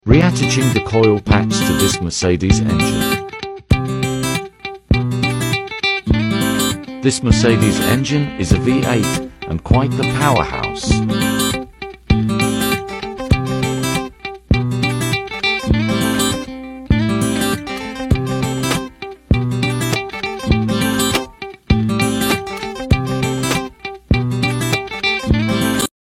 Working on Mercedes engine *fixed sound effects free download